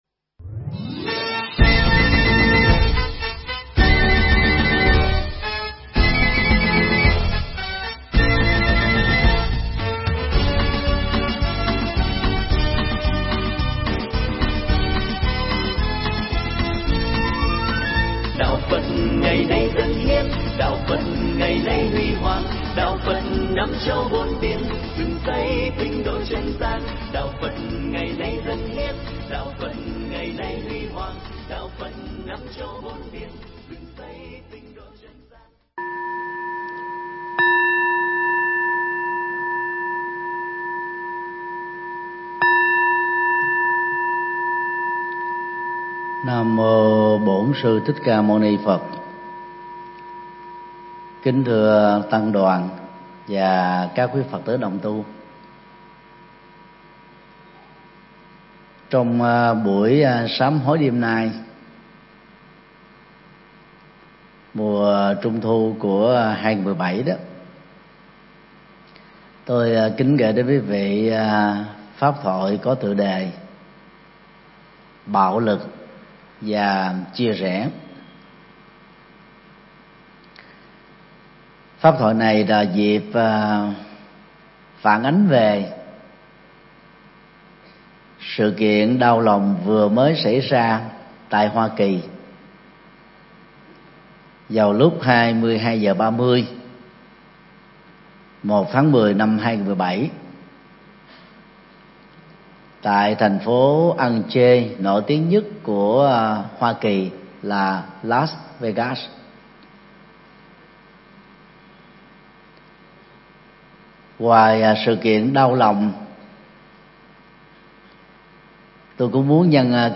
Mp3 Thuyết Pháp Bạo Lực Và Chia Rẽ
giảng tại chùa Giác Ngộ